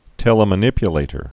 (tĕlə-mə-nĭpyə-lātər)